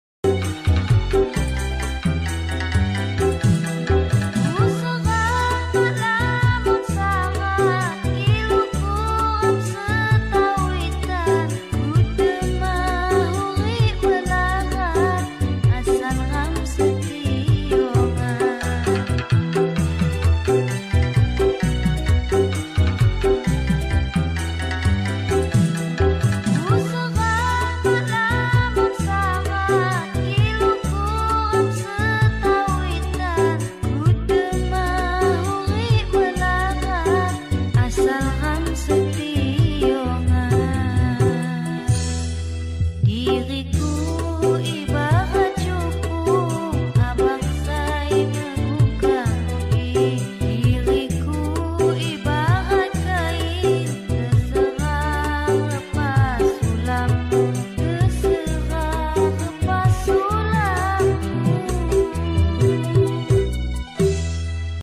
lagu Lampung